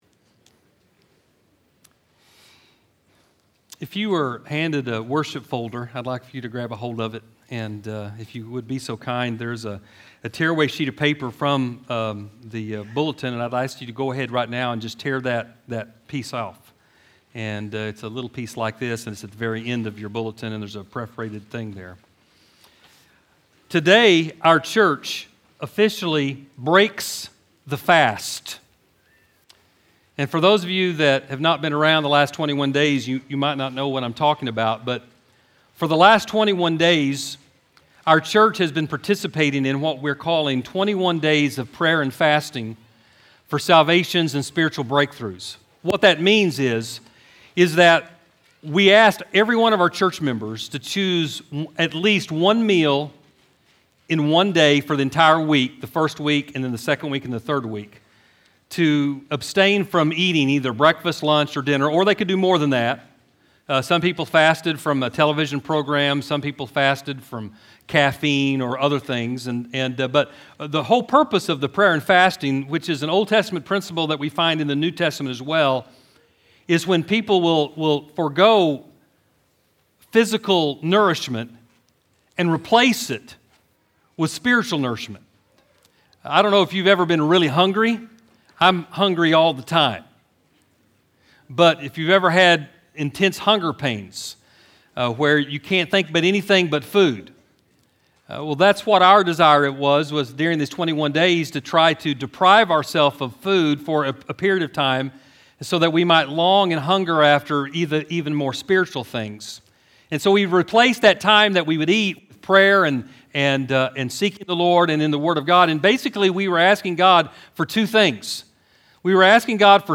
Easter-Sermon.mp3